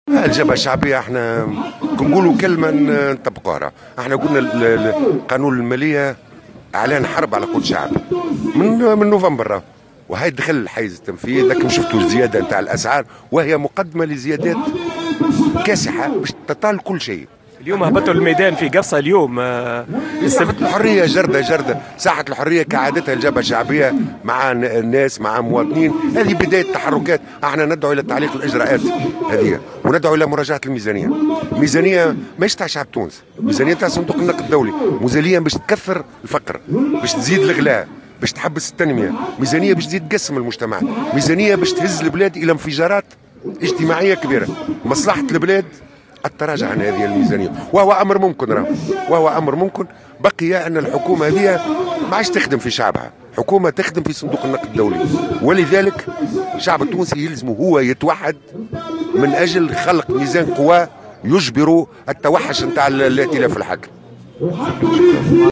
وحذر عمروسية، في تصريح لمراسل الجوهرة أف أم، على هامش اجتماع شعبي للجبهة الشعبية، اليوم السبت في قفصة، من أن الزيادات الأخيرة قد تكون مقدمة لزيادات أخرى "كاسحة" قد تطال كل شيء.